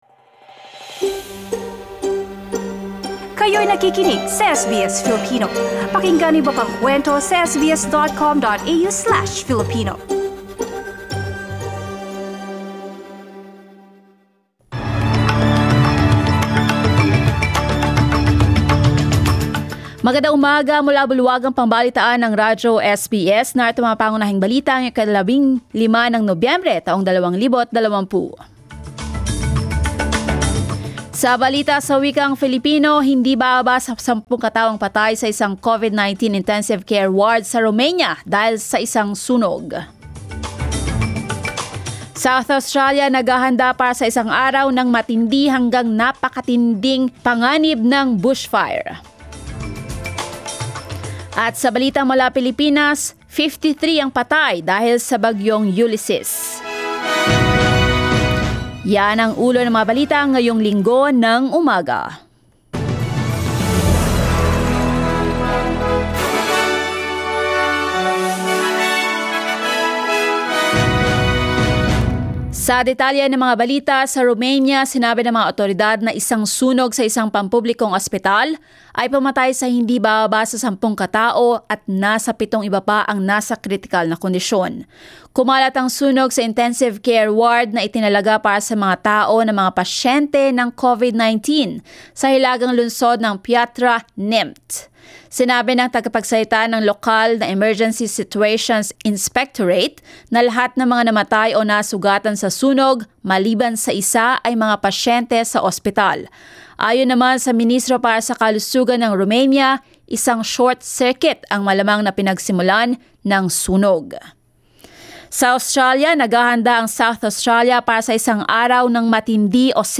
SBS News in Filipino, Sunday 15 November